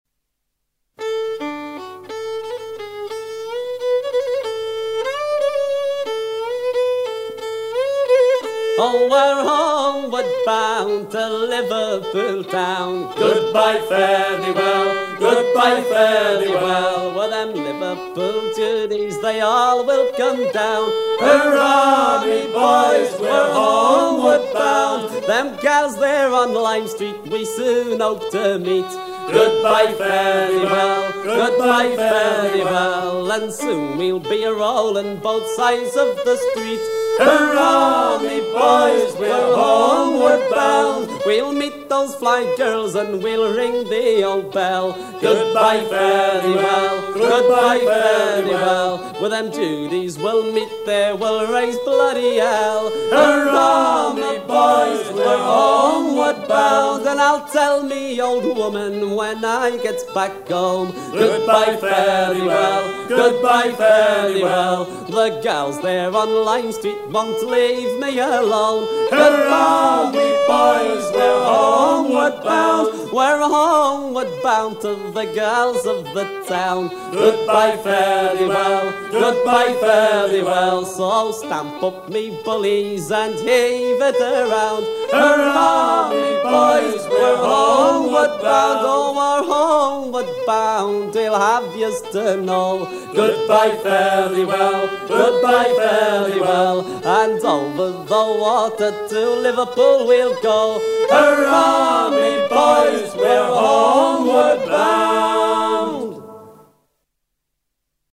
Chants de marins traditionnels
Pièce musicale éditée